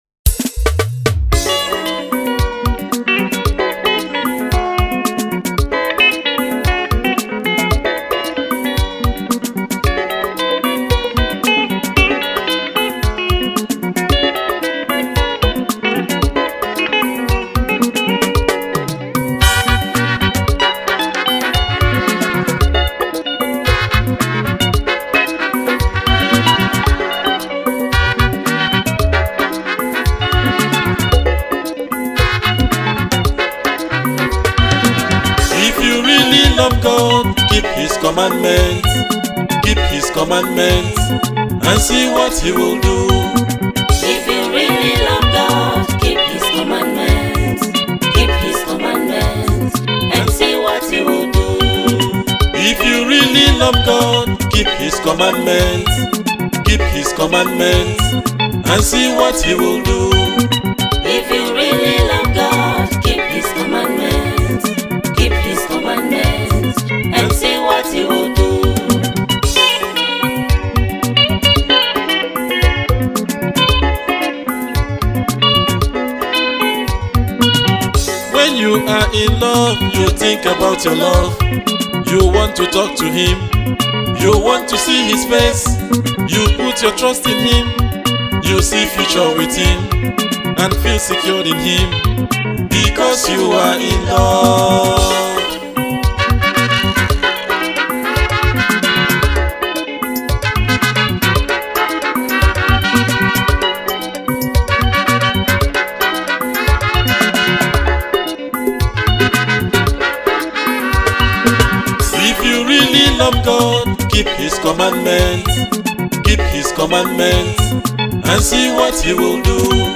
Highlife legend
Gospel Highlife
Thrilling, Unapologetic and liberated